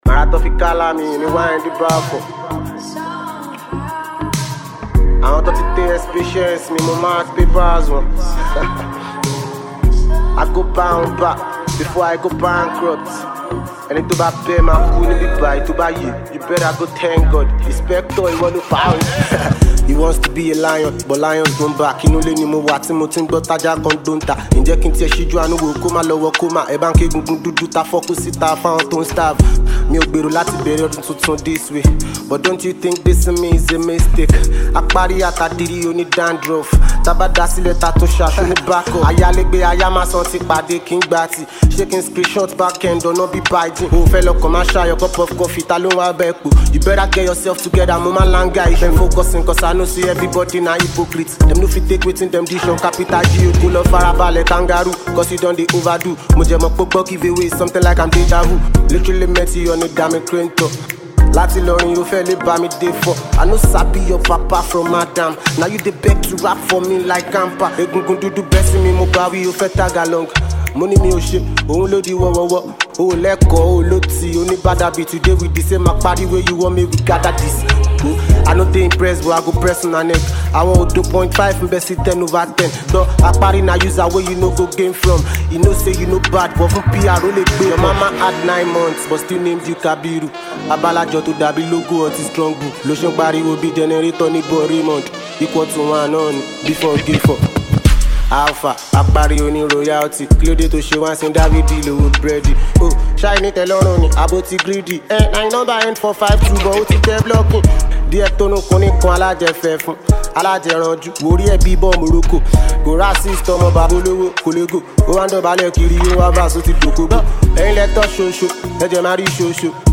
Nigerian Afrobeat
catchy beats
Afrobeat music